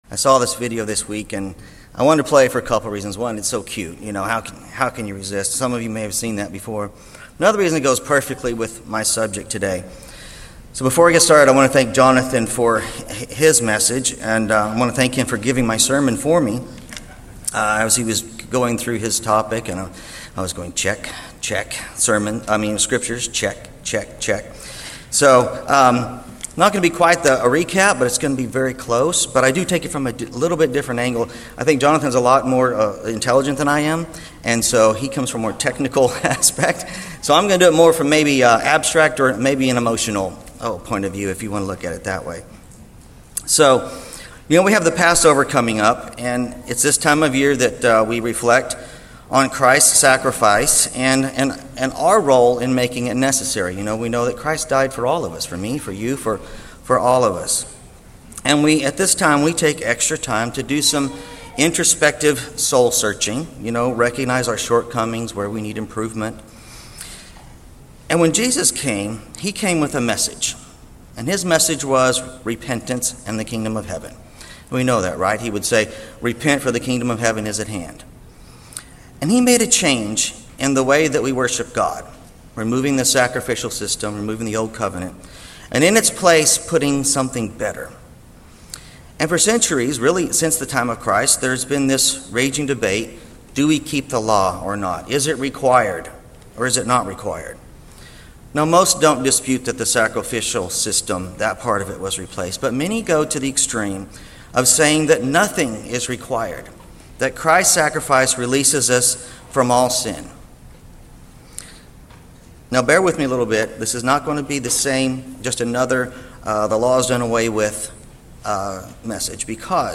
Given in Dallas, TX